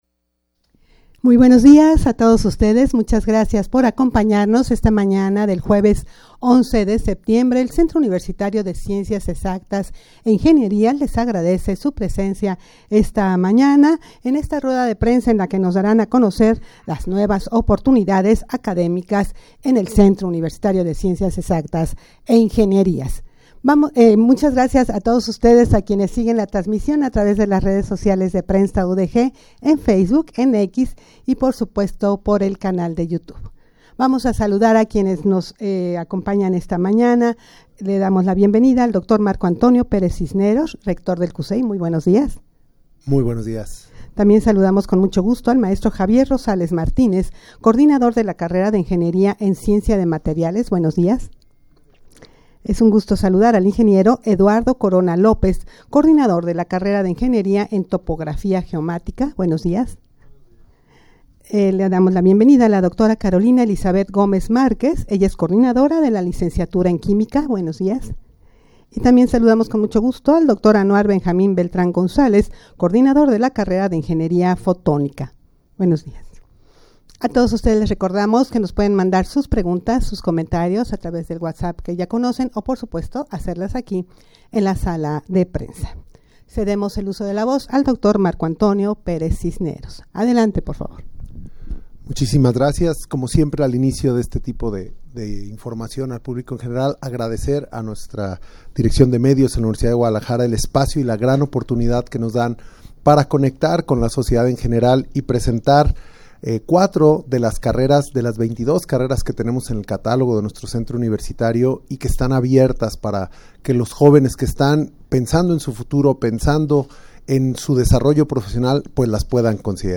Audio de la Rueda de Prensa
rueda-de-prensa-para-dar-a-conocer-nuevas-oportunidades-academicas-en-cucei.mp3